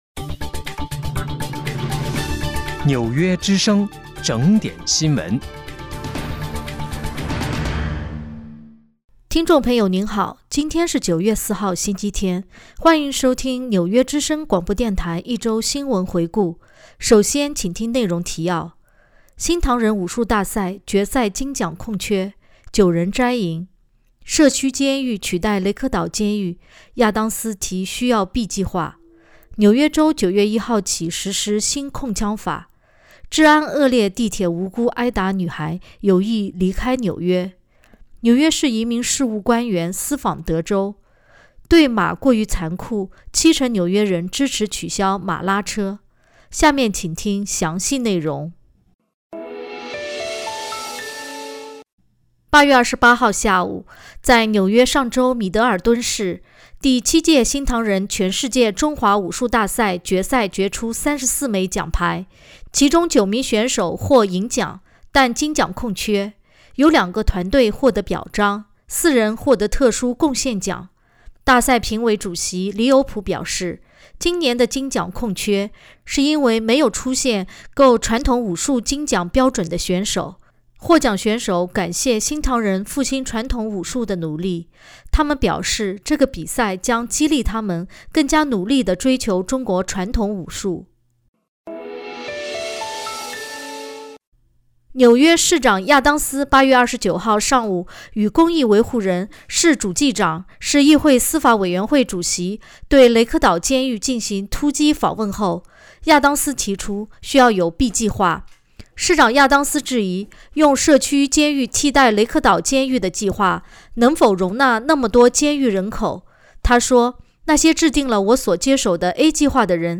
9月4日（星期日）一周新闻回顾